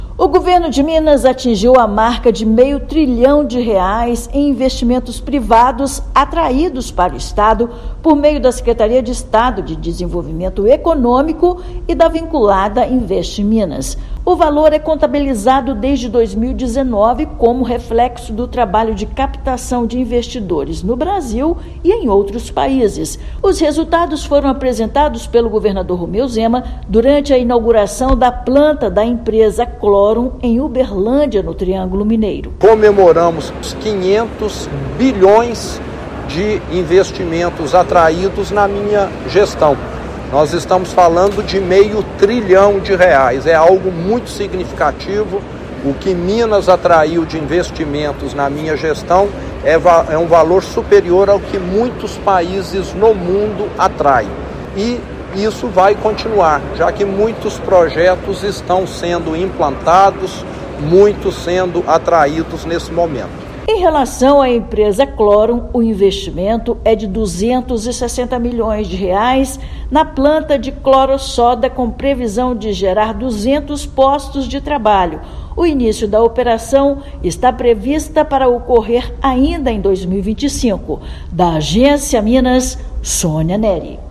[RÁDIO] Governo de Minas atinge marca histórica de atração de meio trilhão de reais em investimentos privados
Anúncio ocorreu durante inauguração de planta, com investimento de R$ 260 milhões, em Uberlândia. Ouça matéria de rádio.